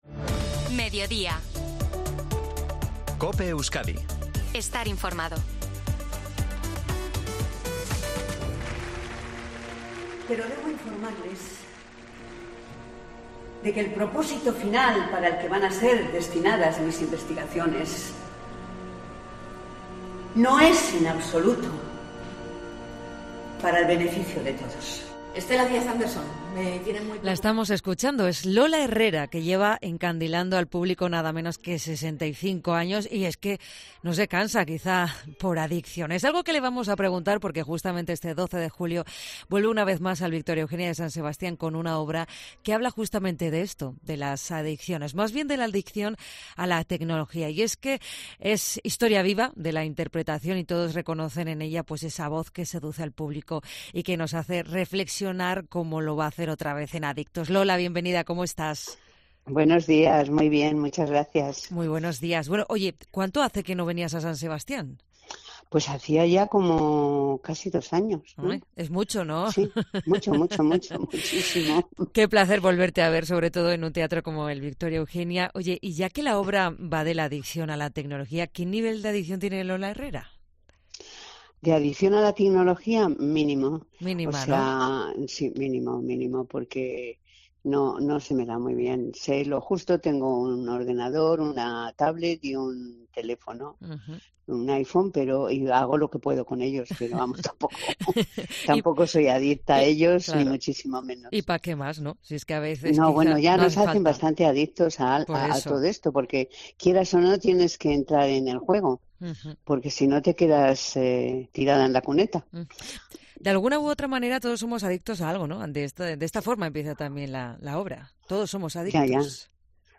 Entrevista a Lola Herrera, en COPE Euskadi